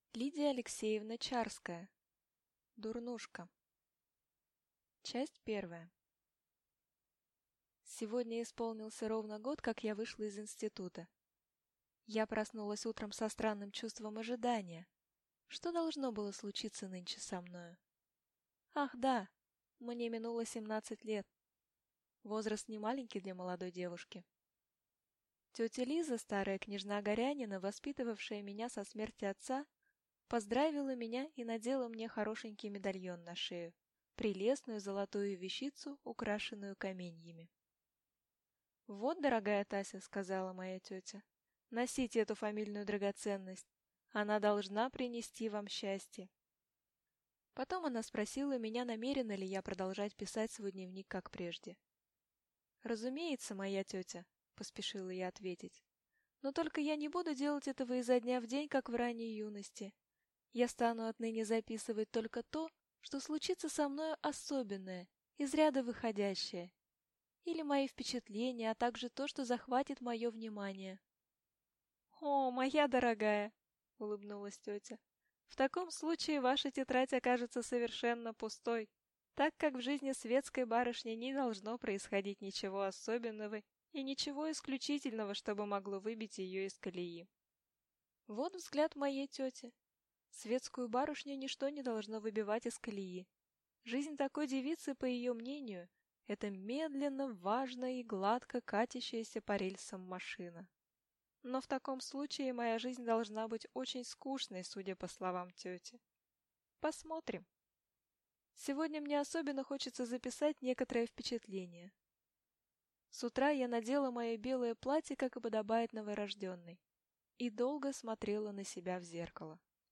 Аудиокнига Дурнушка | Библиотека аудиокниг